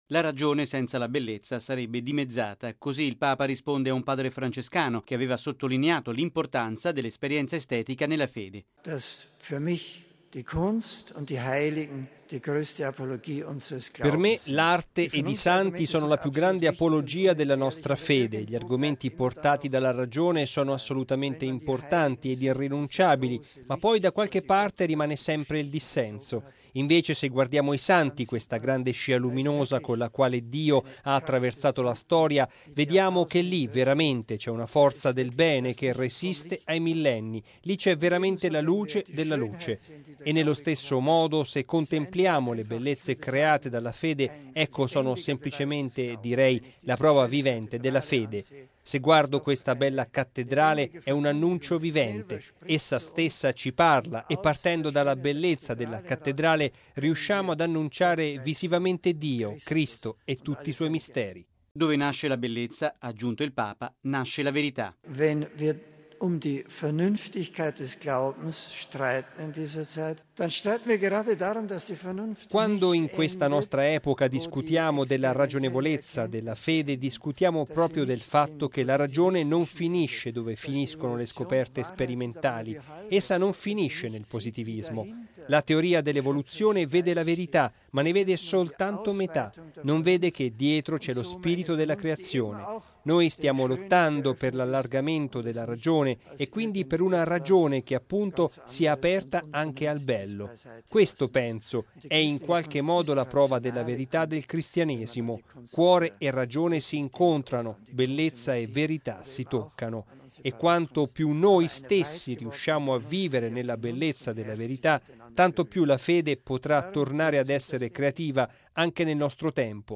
Un parroco gli fa una domanda sulla diminuzione dei preti e sulla possibilità di affidare ai laici alcune funzioni del sacerdote.